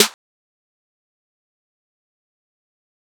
Snare 1.wav